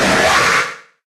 Cri de Hachécateur dans Pokémon HOME.